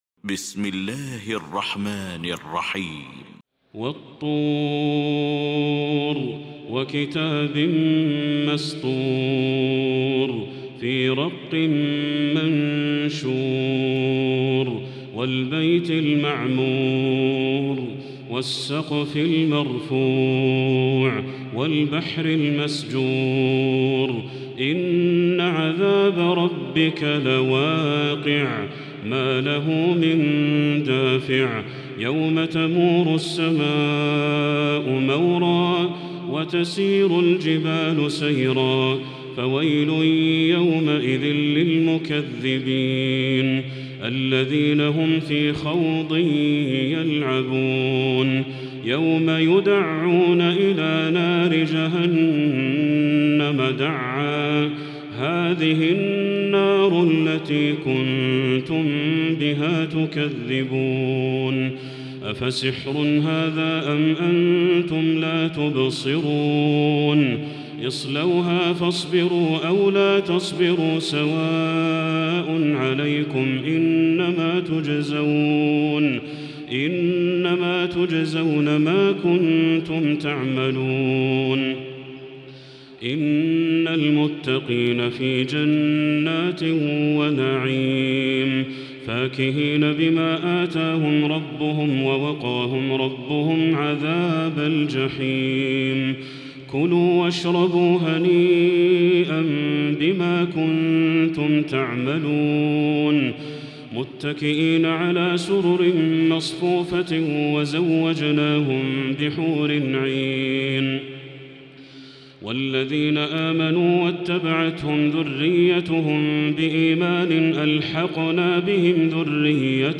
المكان: المسجد الحرام الشيخ: بدر التركي بدر التركي الطور